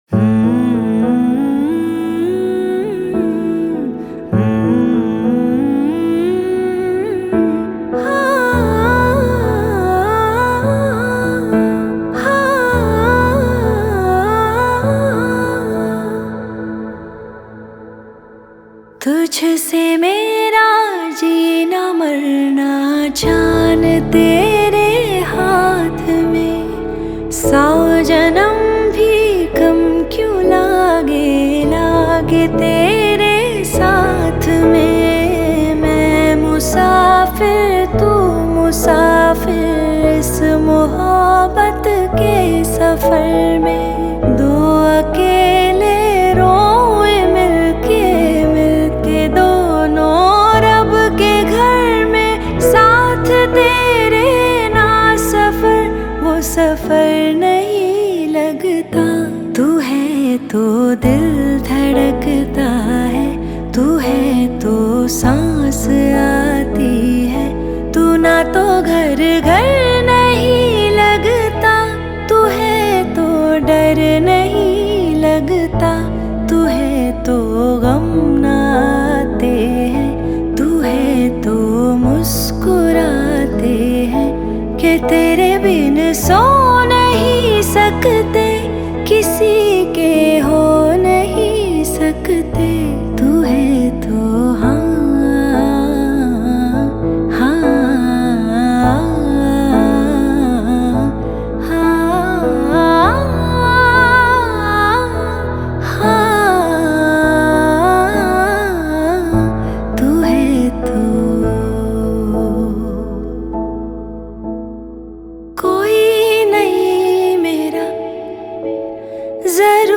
Female Version